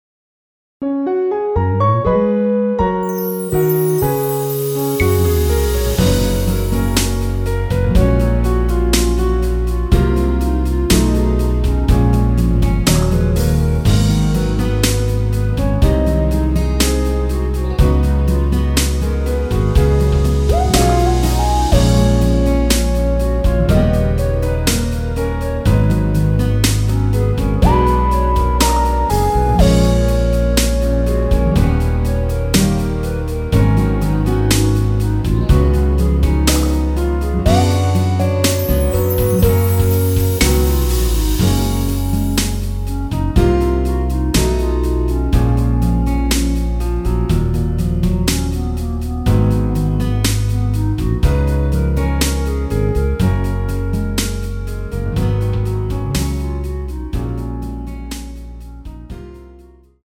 원키에서(-2)내린 멜로디 포함된 MR입니다.
F#
앞부분30초, 뒷부분30초씩 편집해서 올려 드리고 있습니다.
중간에 음이 끈어지고 다시 나오는 이유는